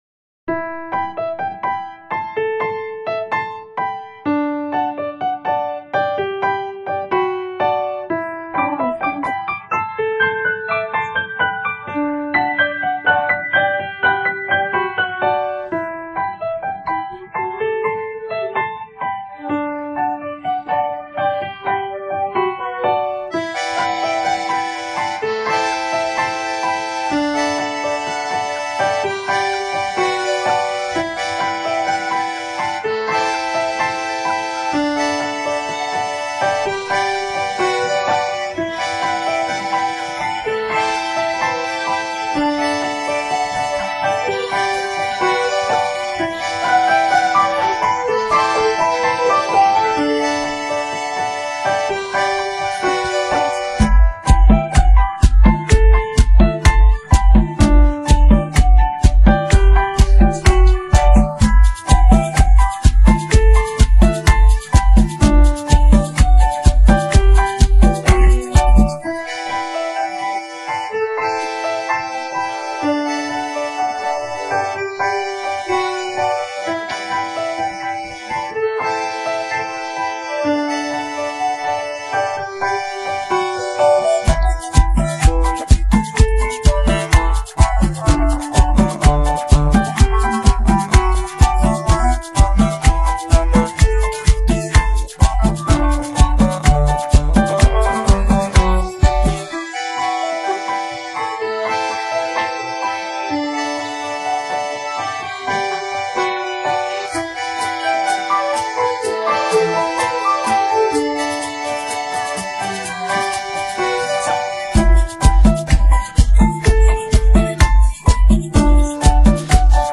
R&B, electrónica, rap..